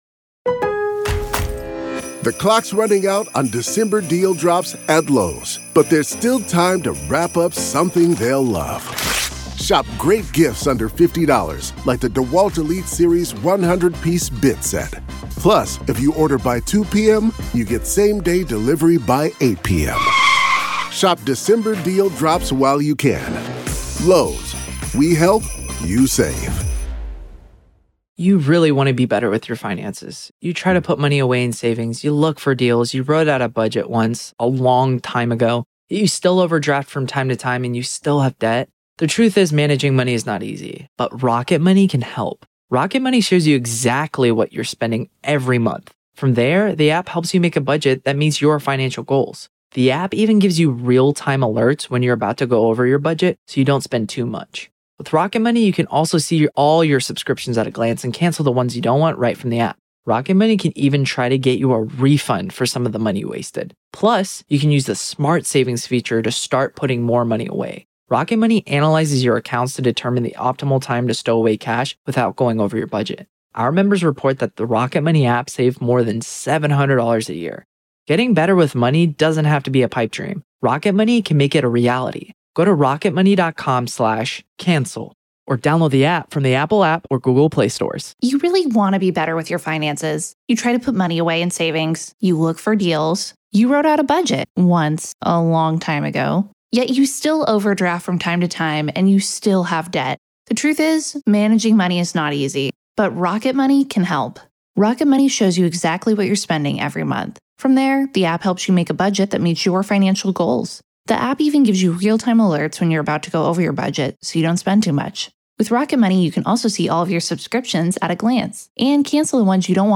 From alleged Brady violations to mysteriously missing evidence, the conversation explores the unsettling pattern of behavior surrounding this case.